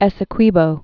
(ĕsĭ-kwēbō)